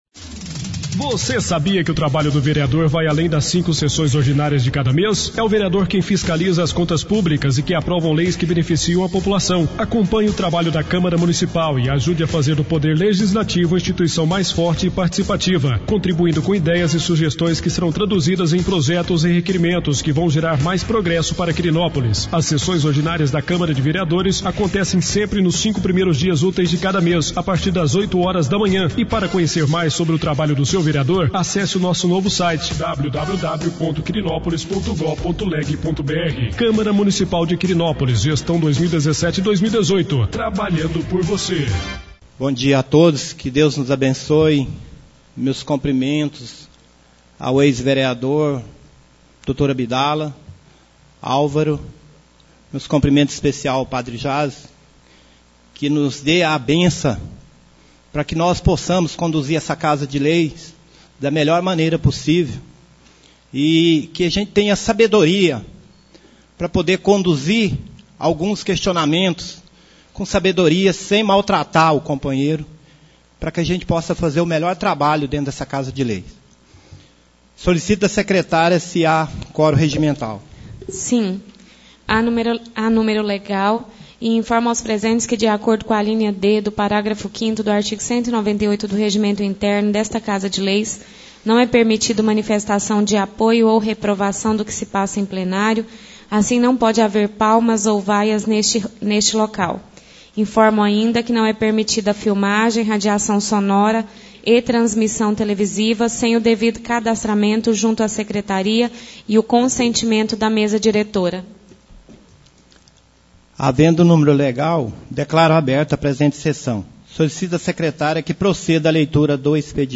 4ª Sessão ordinária do mês de Maio 2017